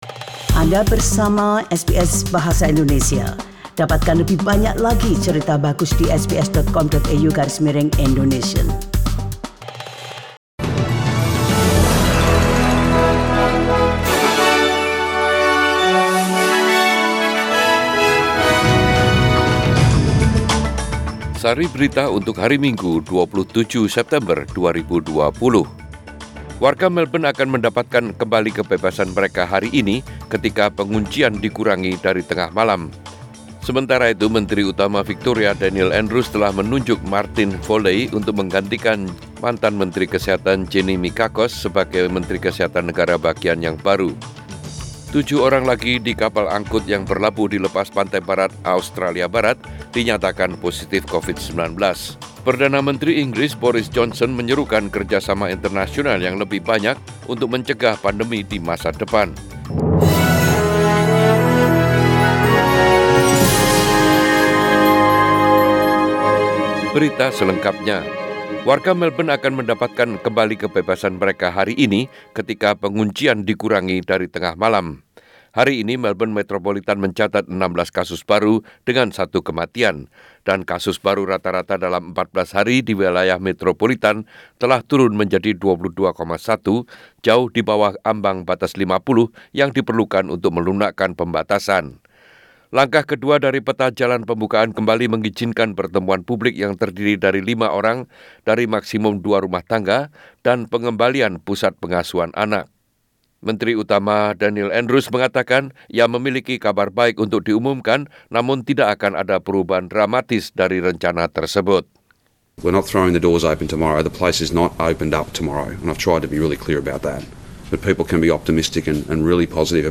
SBS Radio News in Bahasa Indonesia - 27 Sept 2020
Warta Berita Radio SBS Program Bahasa Indonesia Source: SBS